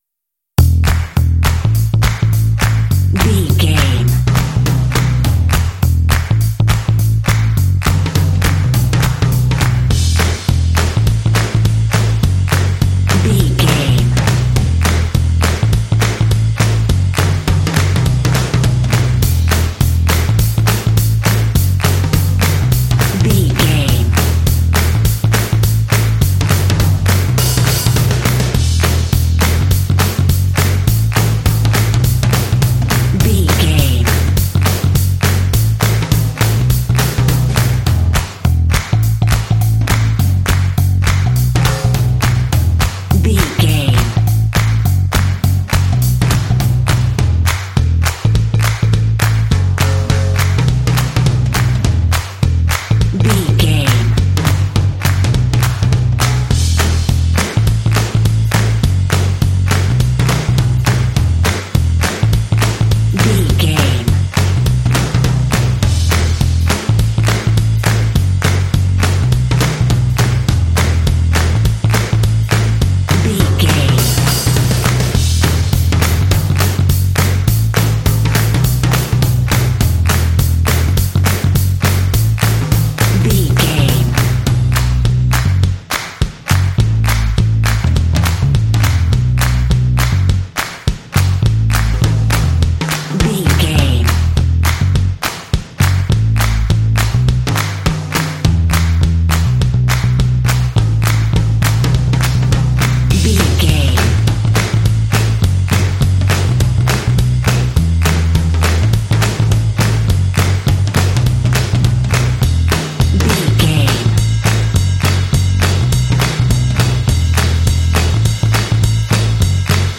Fun and cheerful indie track with piano and “hey” shots.
Uplifting
Ionian/Major
energetic
bouncy
bass guitar
drums
percussion
classic rock
alternative rock